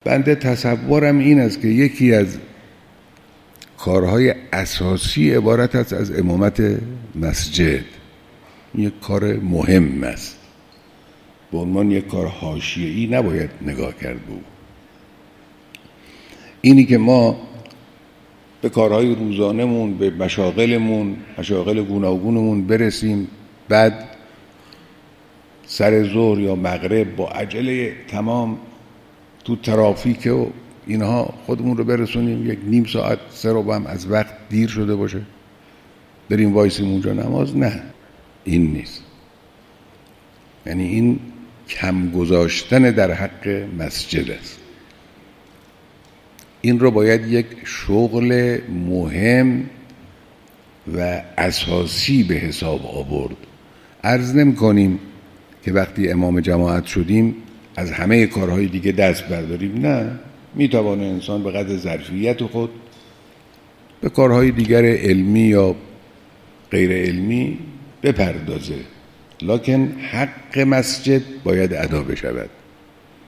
بیانات کوتاه حضرت امام خامنه‌ای(مدظله) در دیدار ائمه جماعات مساجد شهر تهران در روز جهانی مسجد پیرامون نماز